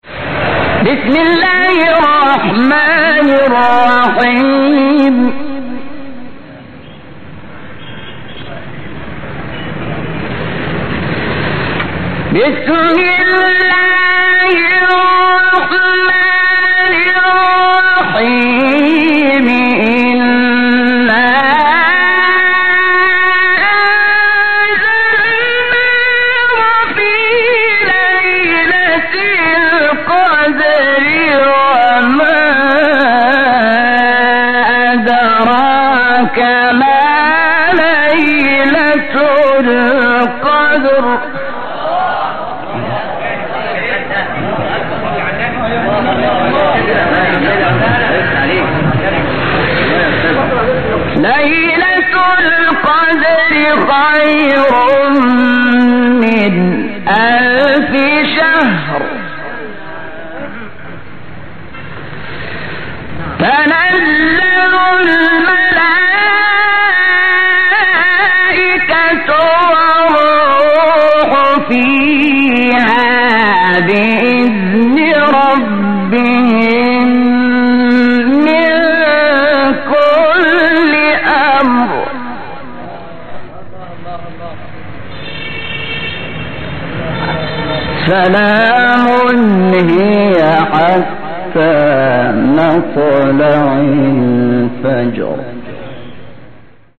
تلاوت سوره قدر استاد شحات مقام رست | نغمات قرآن | دانلود تلاوت قرآن